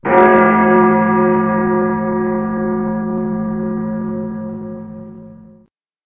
BellGold.ogg